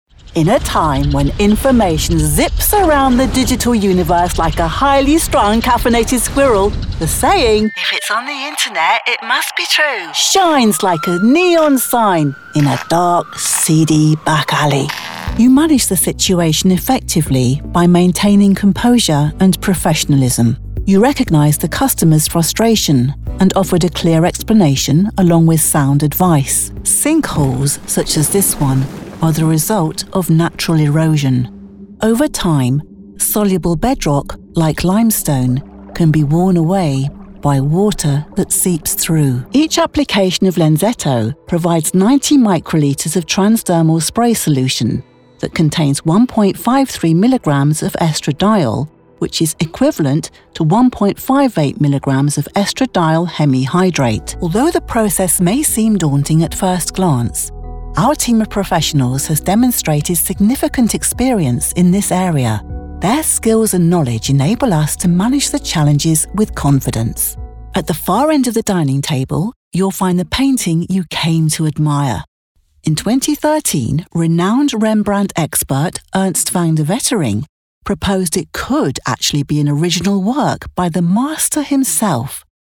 An English voice artist with a warm & reassuring voice
Corporate Reel [ #6 spots 82 seconds]
Received Pronounciation
With a broadcast quality professional home recording space, I have voiced corporate videos, TV, radio and on-line/social media commercials, character roles, telephone/IVR and explainers.
My voice age range is 40’s – 60’s and accent English RP.